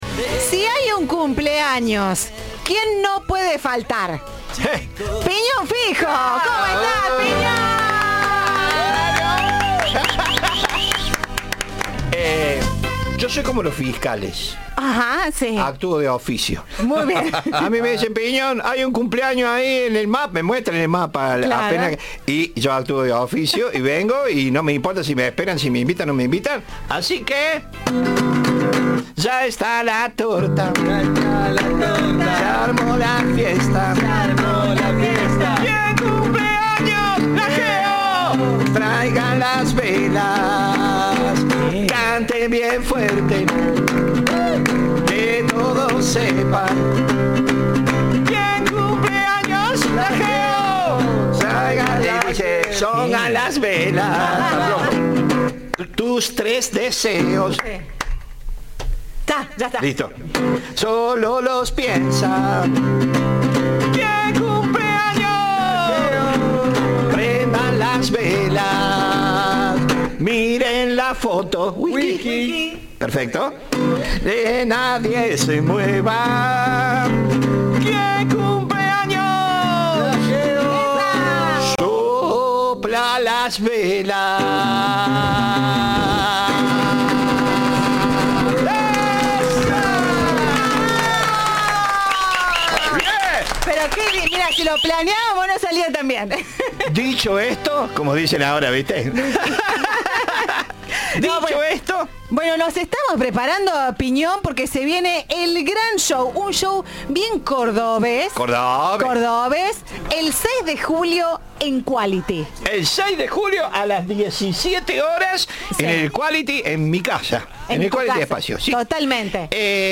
Piñón Fijo desplegó un mundo de sensaciones en los estudios de Cadena 3